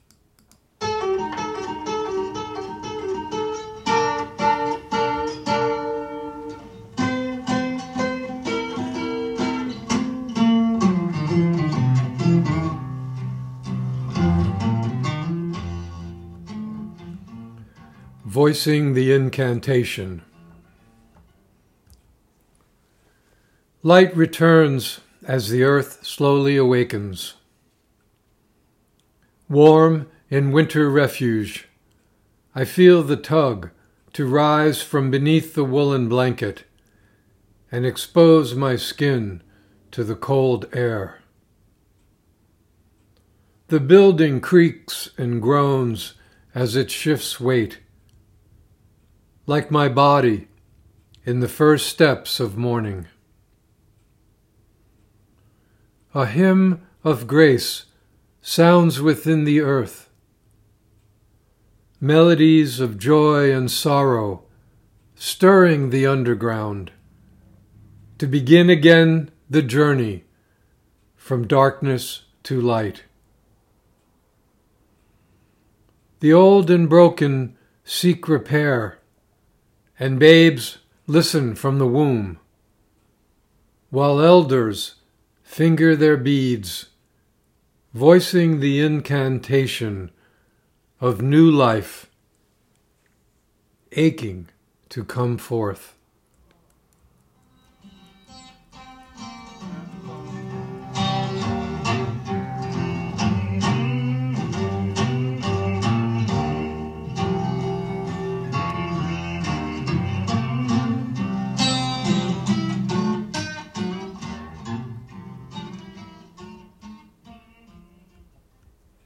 Reading of “Voicing the Incantation” with music by Jimi Hendrix